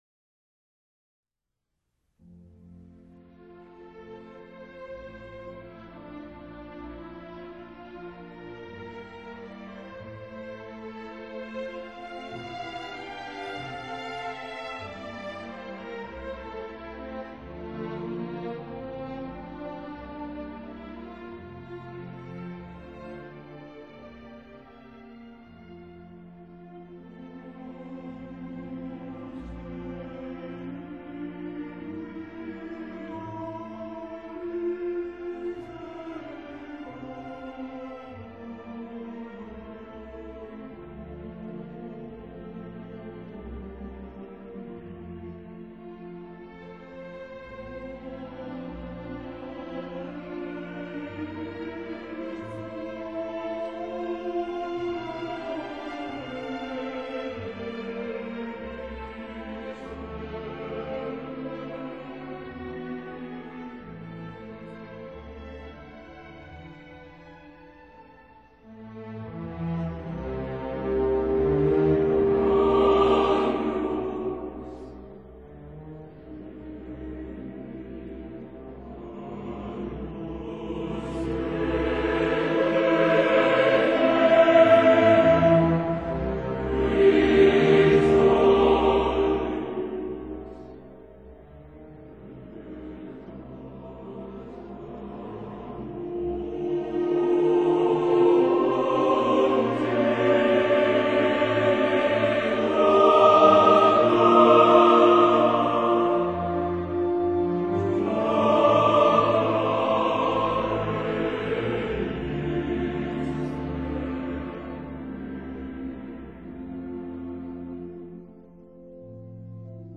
十四、安魂曲Requiem）